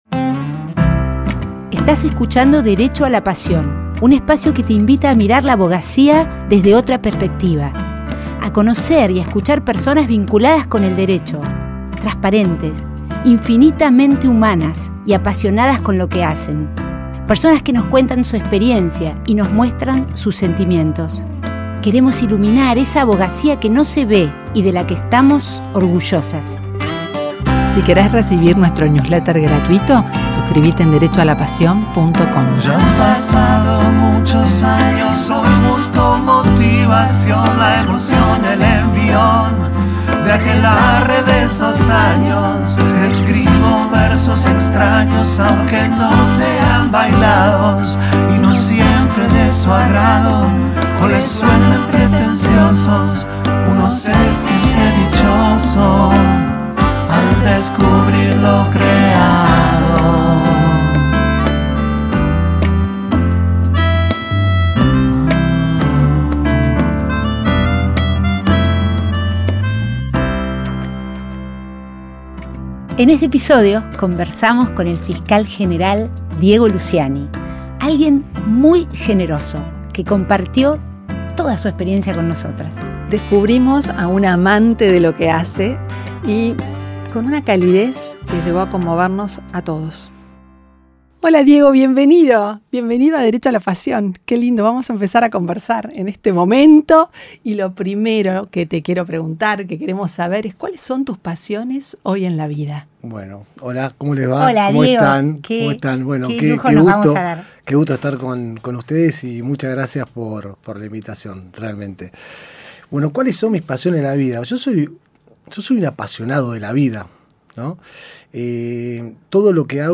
Hoy conversamos con Diego Luciani, Fiscal General ante los Tribunales Orales. Un apasionado de su trabajo, que nos contó su visión sobre el rol de los fiscales, de los jueces y de la sociedad, ante delitos complejos y el trato a las víctimas.